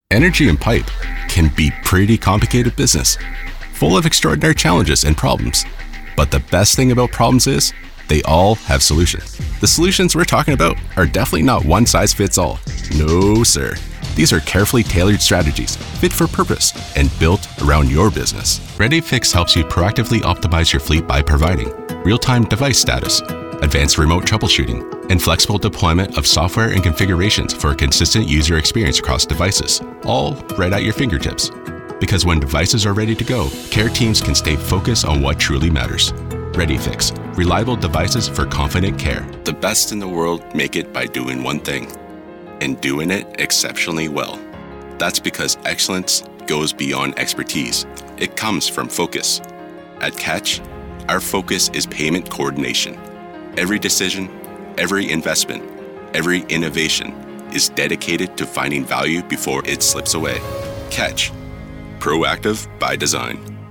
Deep authoritative male voice
Explainer Videos
Genuine Relatable Confident